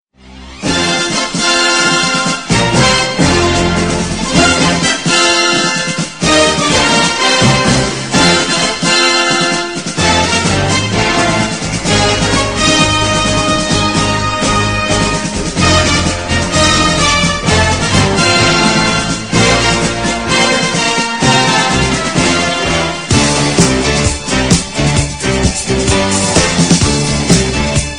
Kategorien: Filmmusik